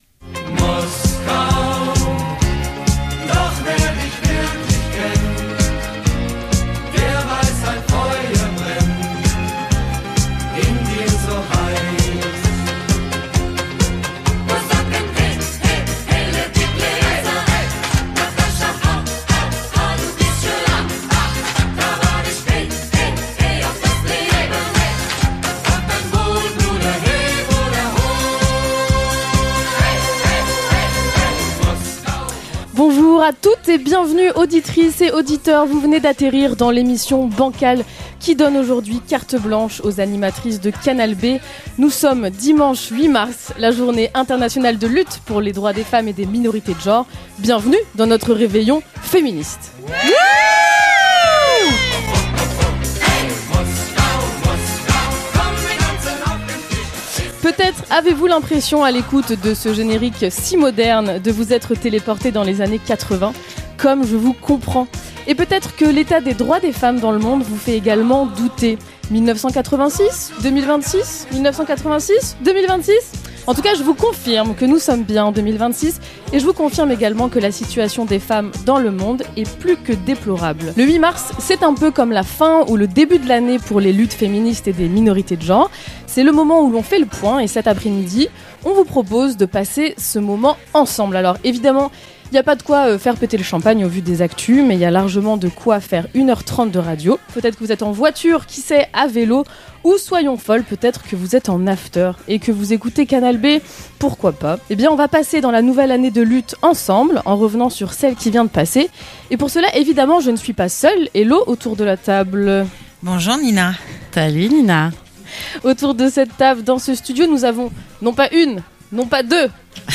8 mars, 9 voix, et autant de luttes. 1h30 de radio par les animatrices de Canal B à l'occasion de la Journée Internationale de Lutte pour les Droits des Femmes et des Minorités de Genre.